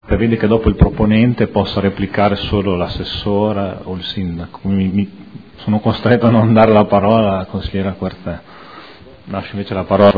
Vice Presidente